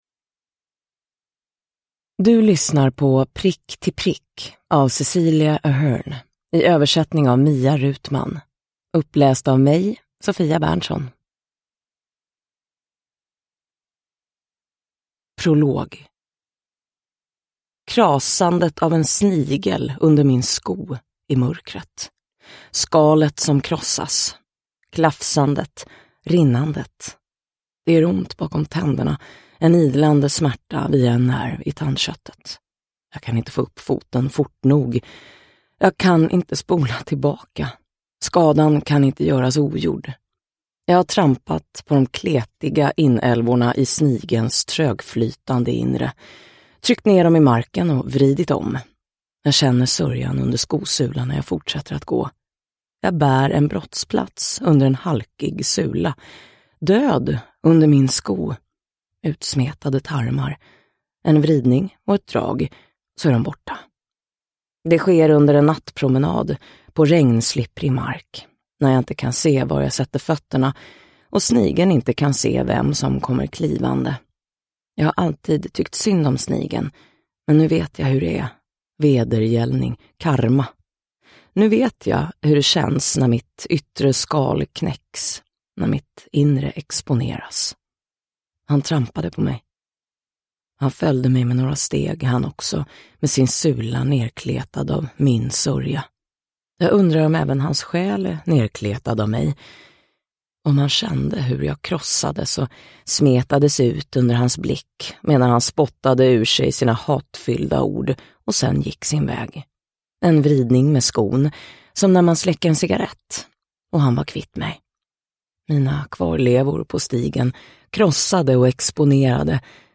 Prick till prick – Ljudbok – Laddas ner